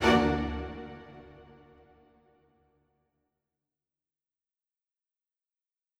an orchestra performing
Strings Hit 7 Staccato
A staccato is a short and fast sound that any orchestral instrument can make.  In this sample, you hear four sections of four different instruments from the orchestra which are violins, violas, violoncellos and double basses.
Strings-Hit-7-Staccato.wav